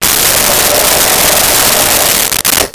Blow Dryer 04
Blow Dryer 04.wav